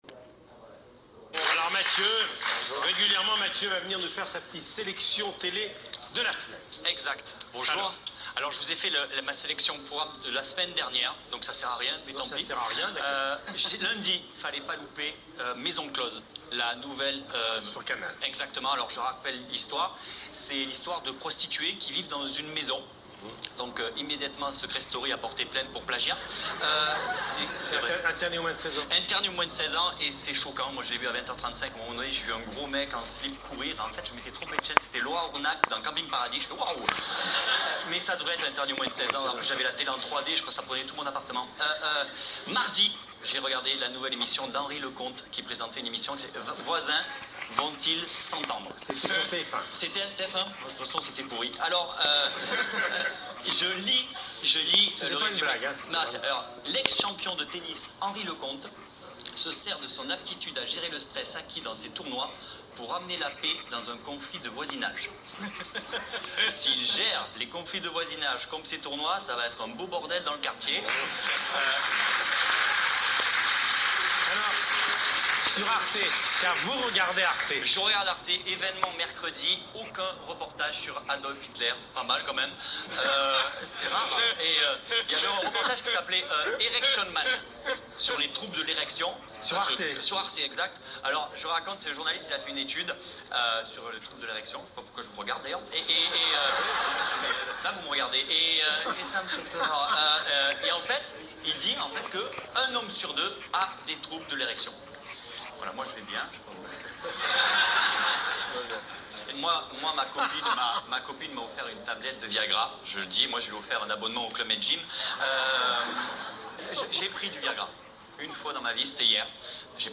Dimanche, l'humoriste Mathieu Madenian était invité seul, en pleine intimité, (Covid oblige), sur le divan de Michel Drucker. Le rappel de quelques séquences humoristiques qui déstabilisent tous les invités, valent le détour pour comprendre la suite(désolé pour la mauvaise qualité, c'est un enregistrement avec casque d'un enregistrement télé).